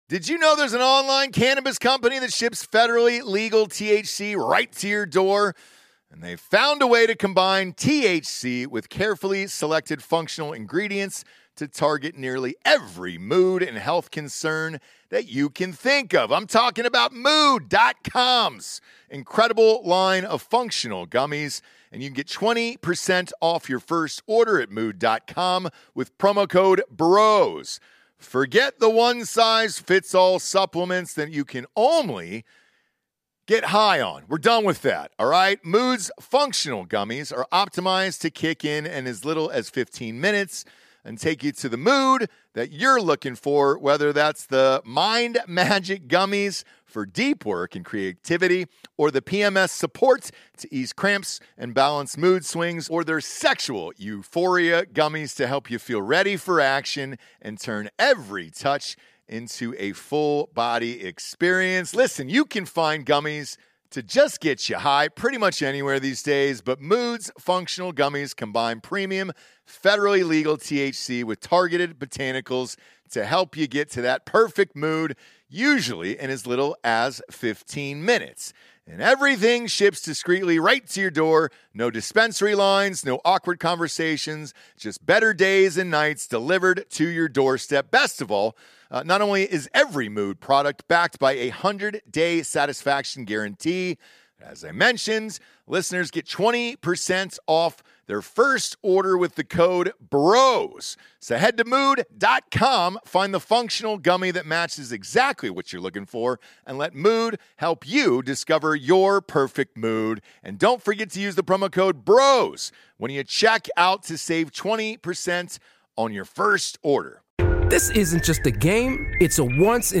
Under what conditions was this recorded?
Episode 882 - LIVE From The "Field Of Dreams"